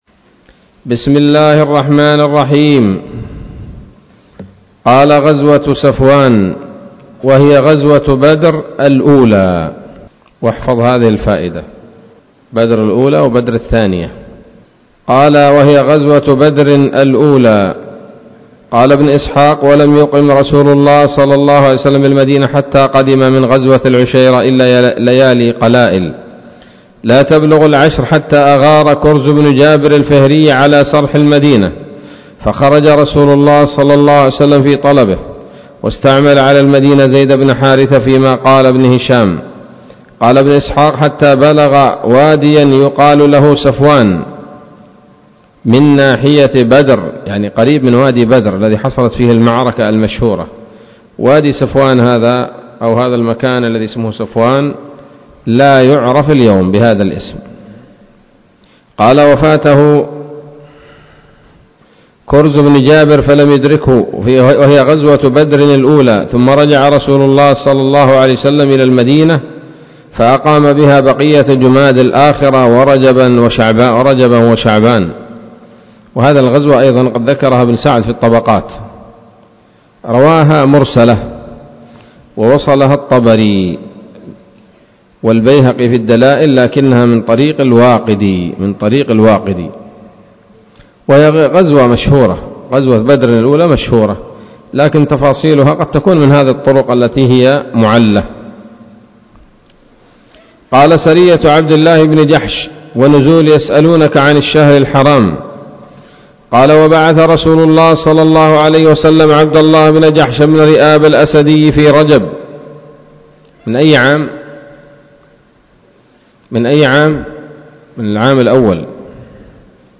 الدرس السادس بعد المائة من التعليق على كتاب السيرة النبوية لابن هشام